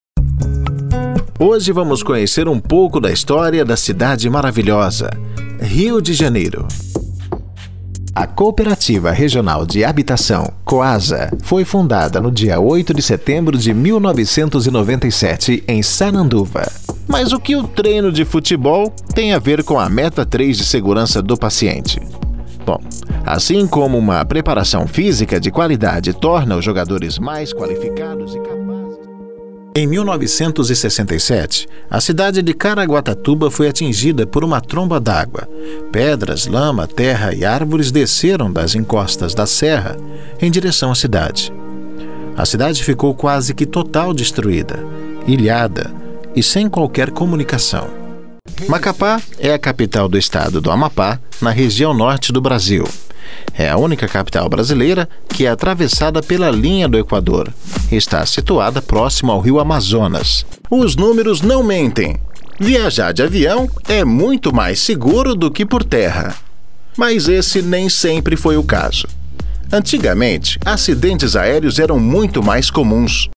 DOCUMENTARIOS E APRESENTACOES